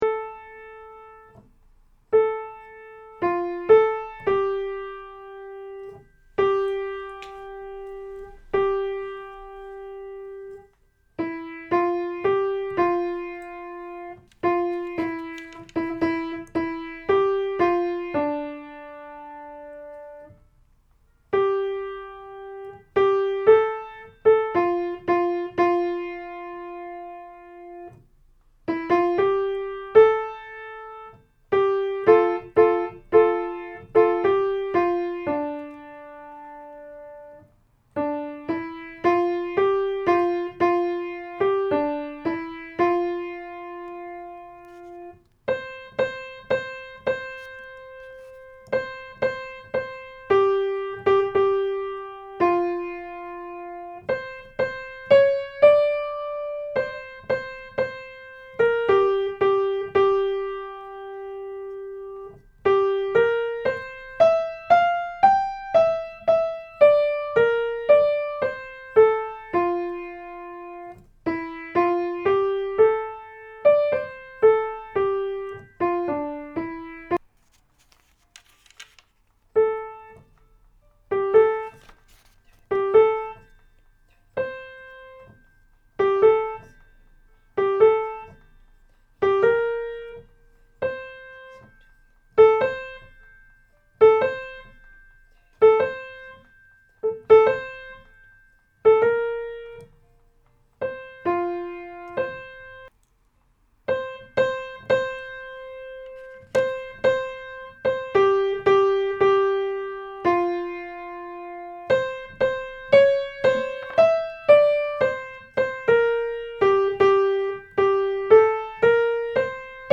Melody: Traditional Arr: Jamey Ray
Danny_Boy-Tenor2.mp3